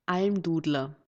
Almdudler (German pronunciation: [ˈalmˌduːdlɐ]
De-at_Almdudler.ogg.mp3